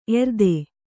♪ erde